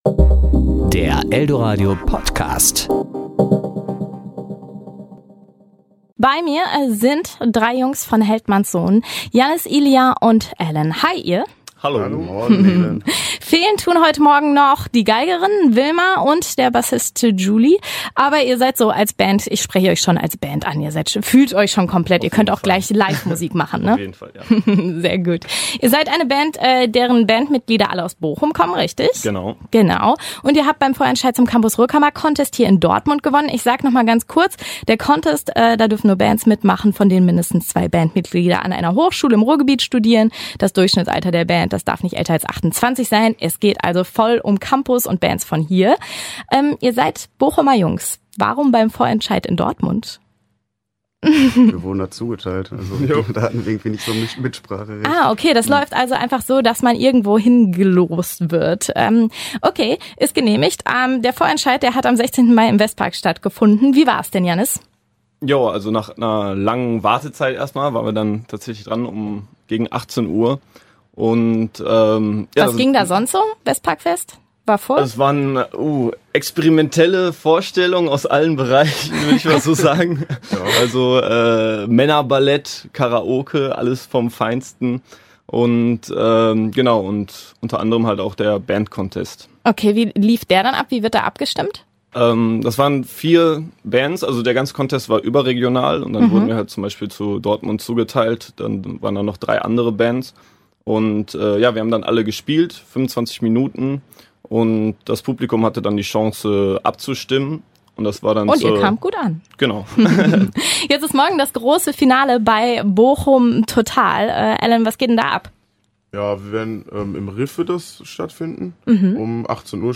Serie: Interview
podcast_interview_heldmanns_sohn_0.mp3